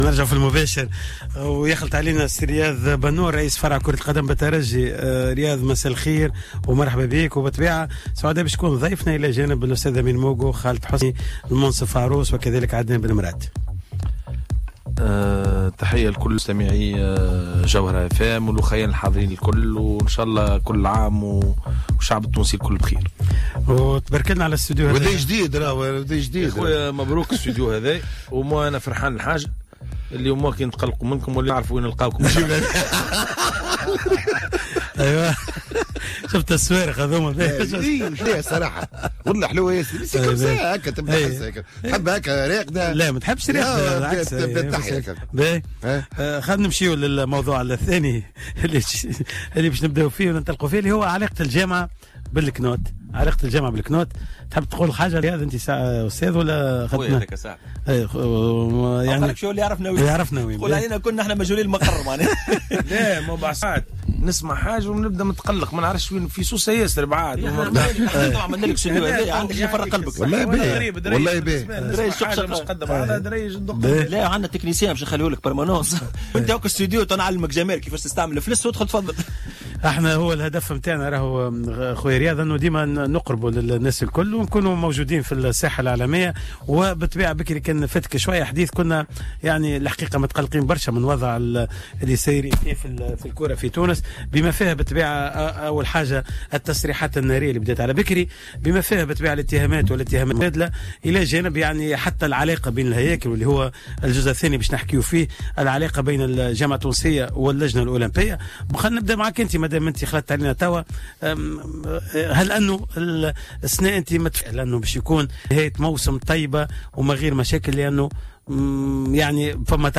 تناول برنامج cartes sur table الذي بث للمرة الأولى من الإستديو الجديد لراديو جوهرة أف أم...